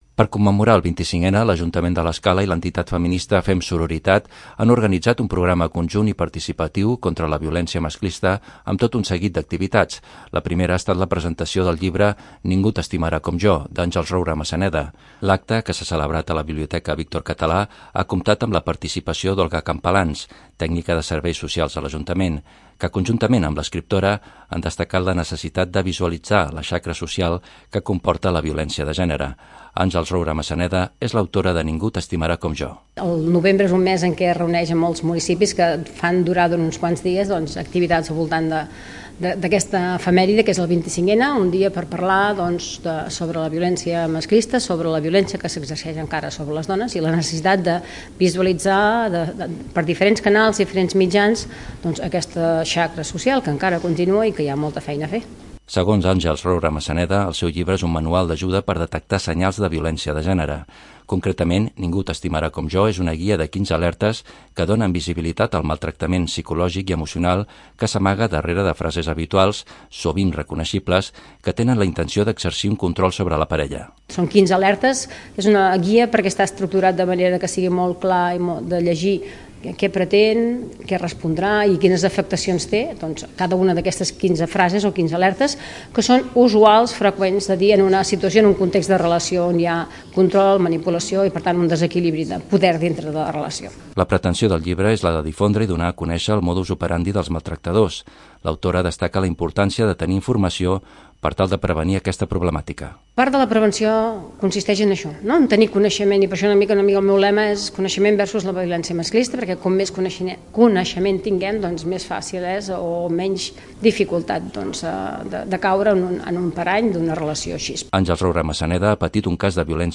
En el marc de la commemoració del 25N, la Biblioteca Víctor Català ha acollit la presentació del llibre “Ningú t’estimarà com jo”.